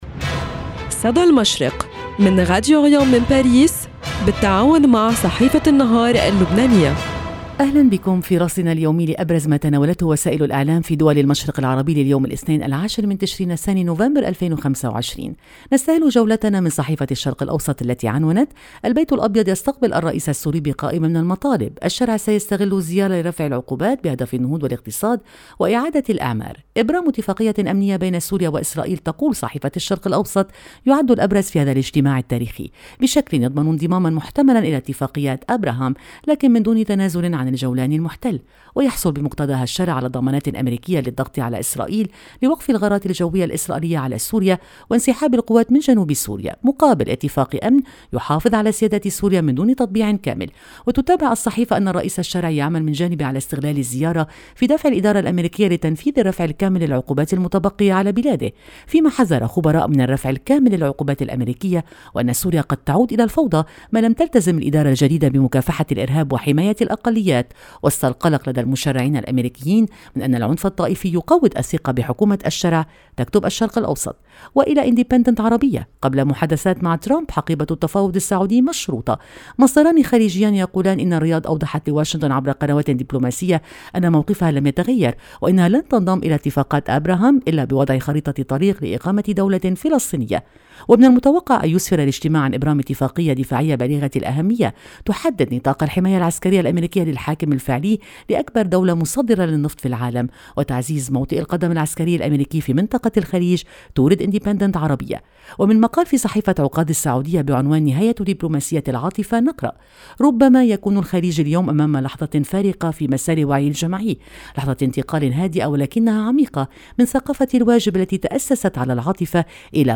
إعداد وتقديم